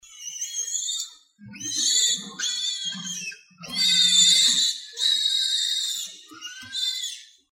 ZhuChiXuJiaoSheng.mp3